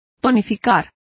Complete with pronunciation of the translation of invigorate.